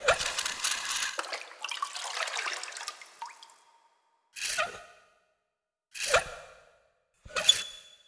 SFX_GettingWater_Well_01_Reverb.wav